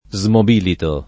smobilito [ @ mob & lito ]